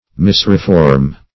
Misreform \Mis`re*form"\